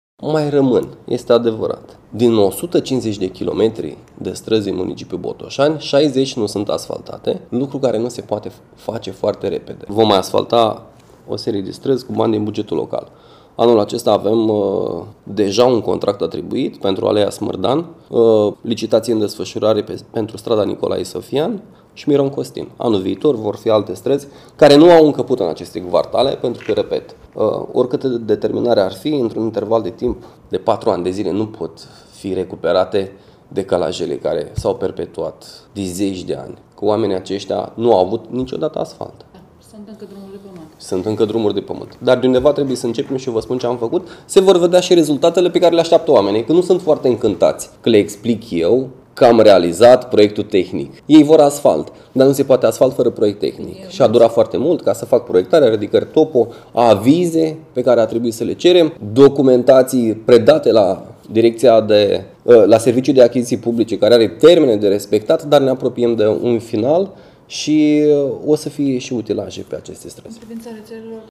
Primarul Cosmin Andrei: